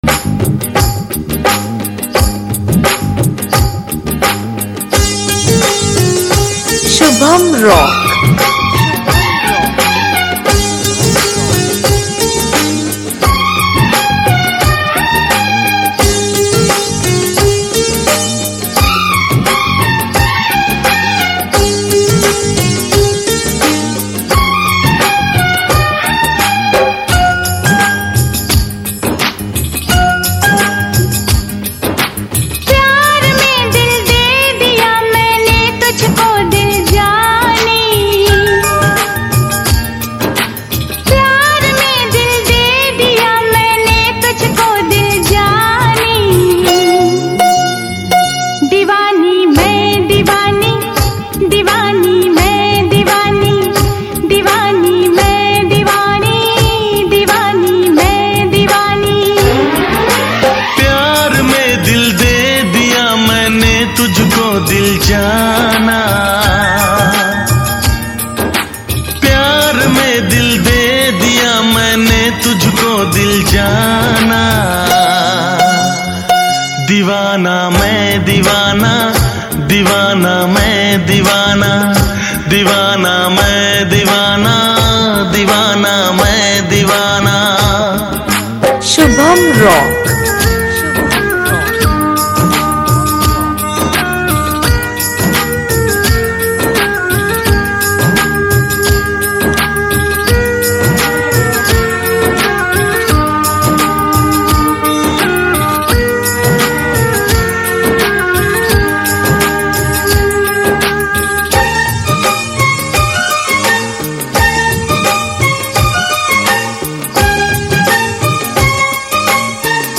Mela Competition Filters Song
Mela Competition Filter Song